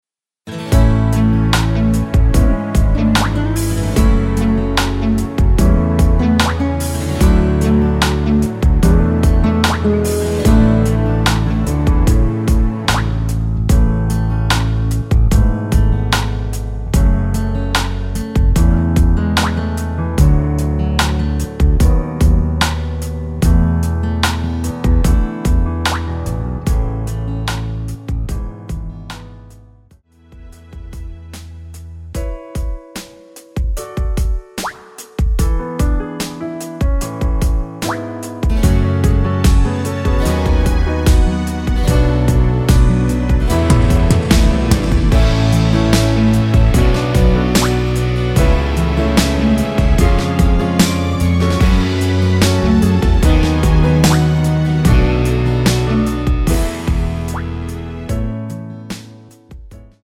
◈ 곡명 옆 (-1)은 반음 내림, (+1)은 반음 올림 입니다.
앞부분30초, 뒷부분30초씩 편집해서 올려 드리고 있습니다.
중간에 음이 끈어지고 다시 나오는 이유는